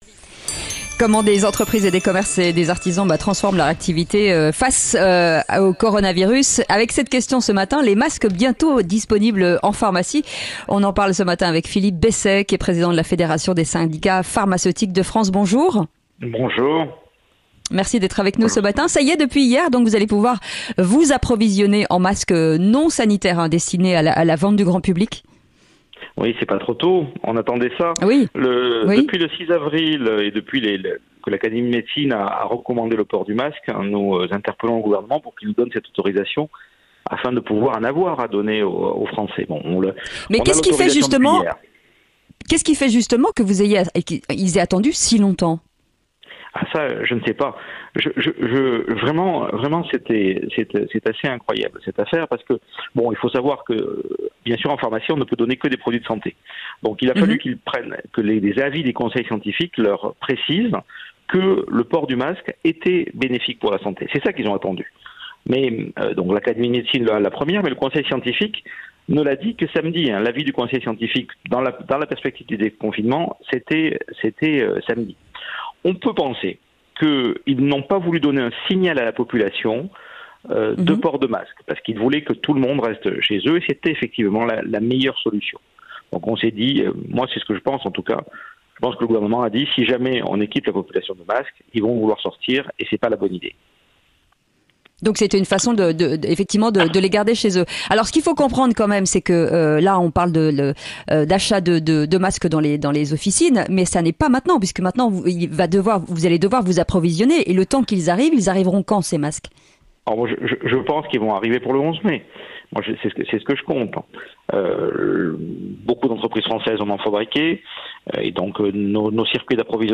Sud Radio à votre service dans Le Grand Matin Sud Radio à 6h50 avec FIDUCIAL.